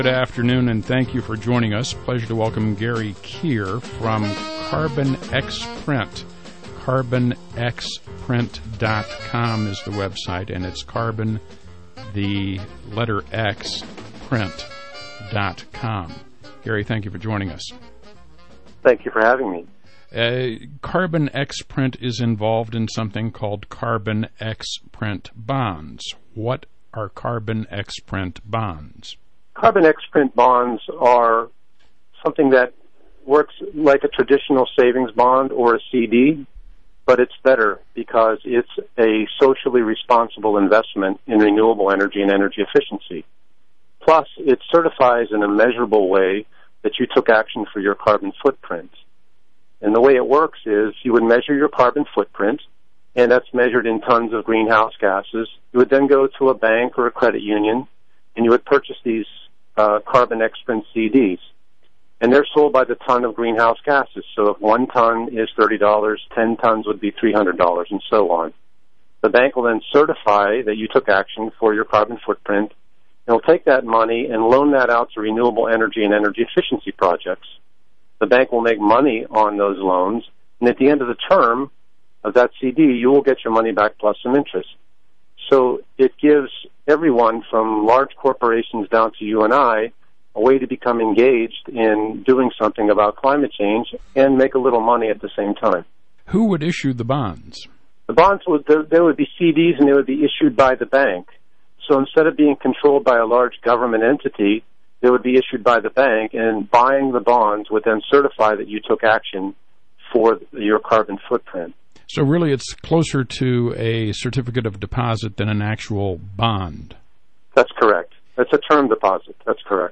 Money Radio – Phoenix, AZ and Palm Springs, CA – Interview